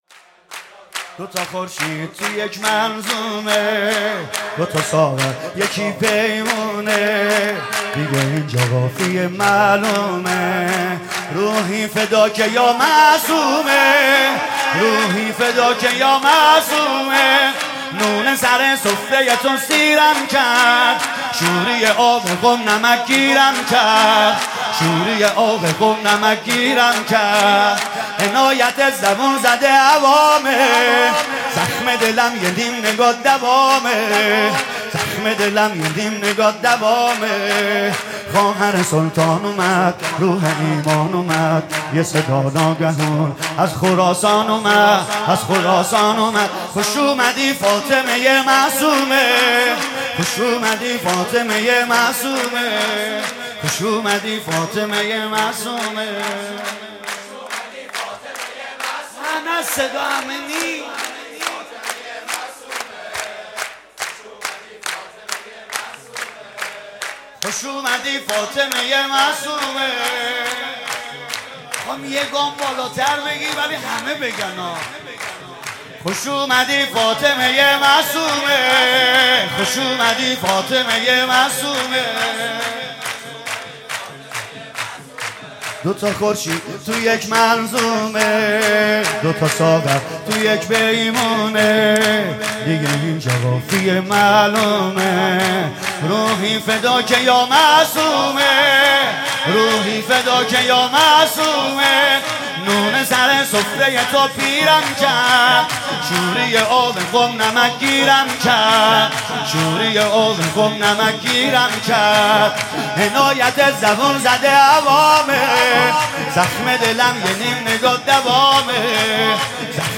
سرود: دوتا خورشید تو یک منظومه